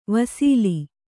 ♪ vasīli